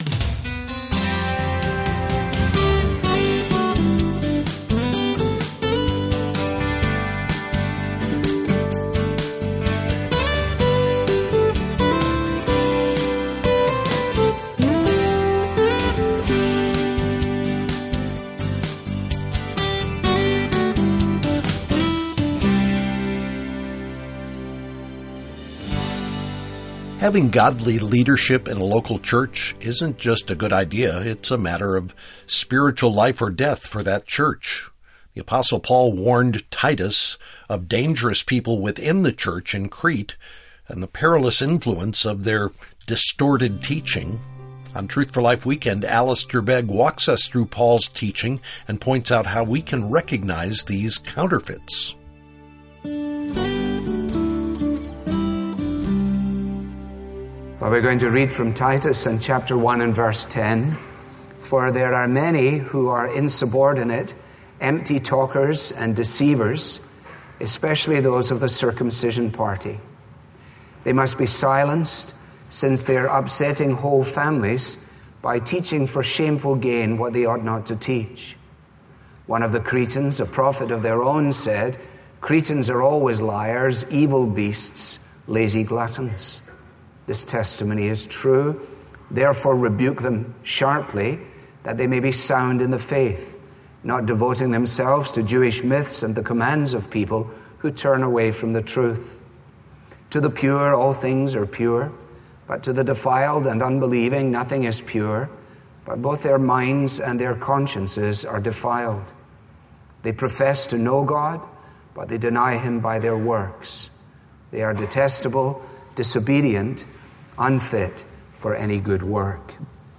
The apostle Paul warned Titus of dangerous people within the church at Crete and the impact of their distorted teaching. On Truth For Life, Alistair Begg teaches us how to recognize such counterfeits. ----------------------------------------- • Click here and look for "FROM THE SERMON" to stream or read the full message.